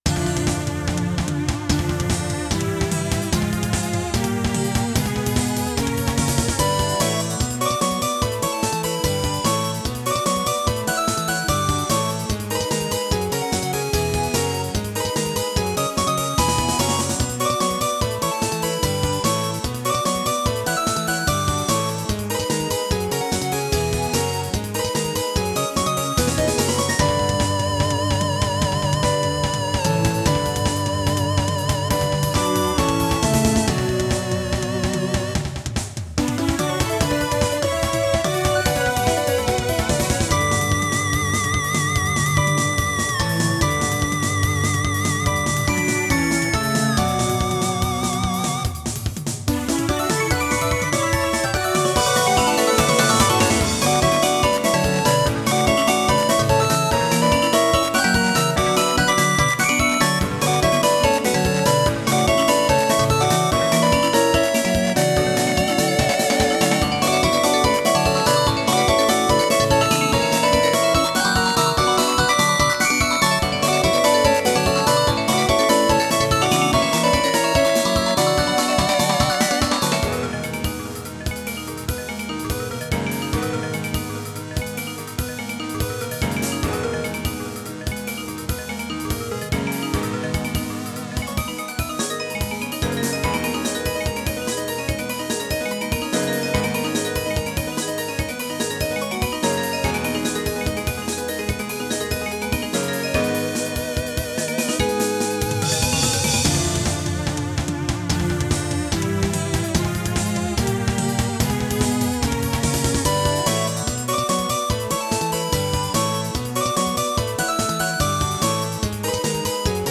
使用音源为SC-D70。